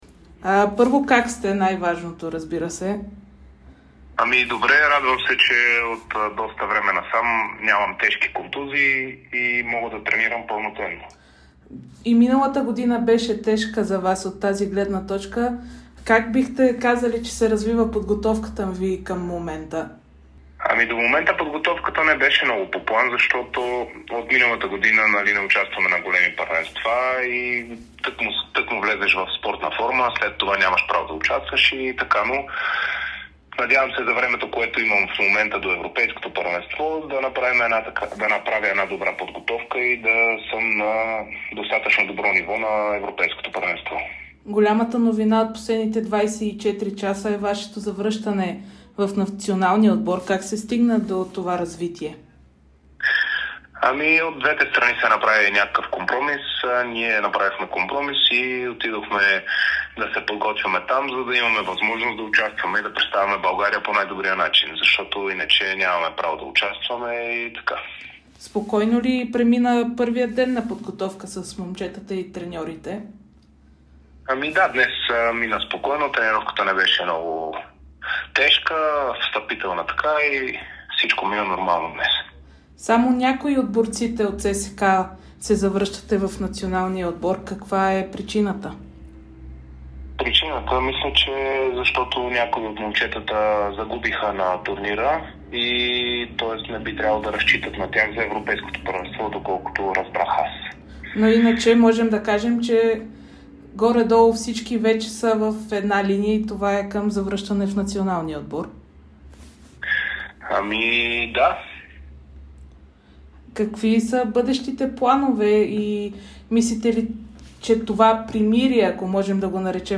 Милов говори специално пред Дарик радио и dsport за целта си на Европейското първенство и как се е стигнало до завръщането в националния отбор.